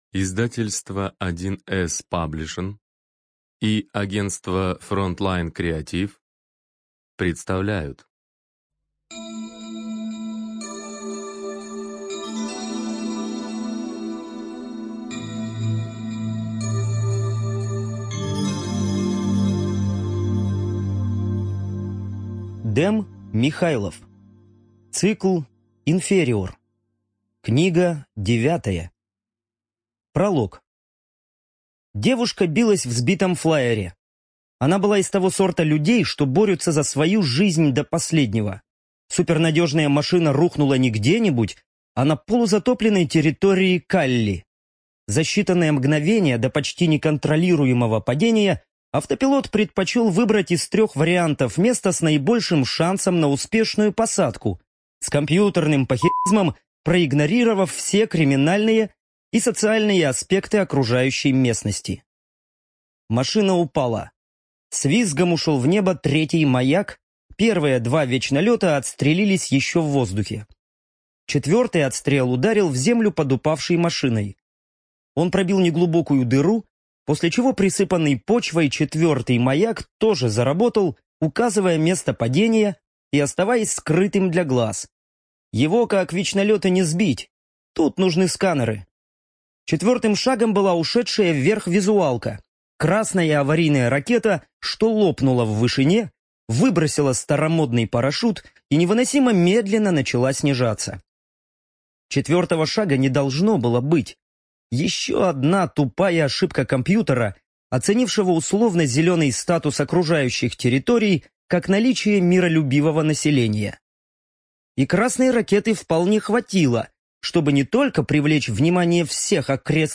Студия звукозаписи1С-Паблишинг